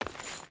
Stone Chain Run 1.ogg